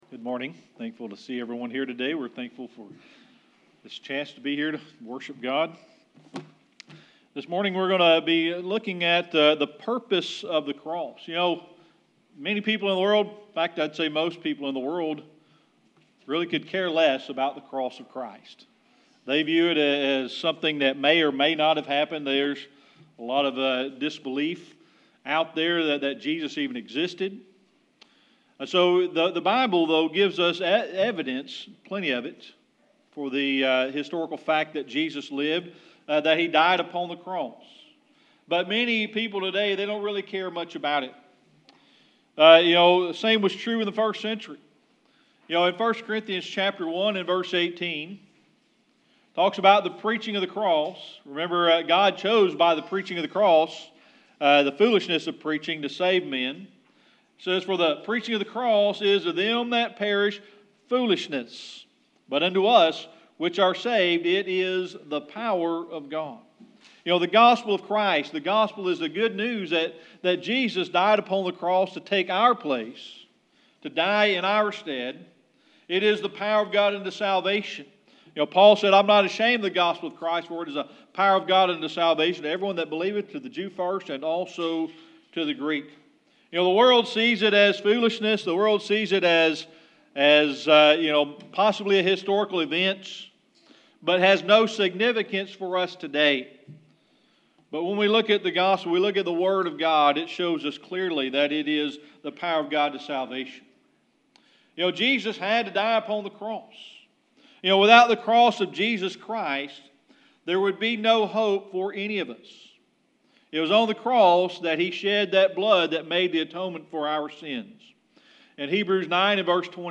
1 Corinthians 1:18 Service Type: Sunday Morning Worship This morning we are going to be looking at the purpose of the cross.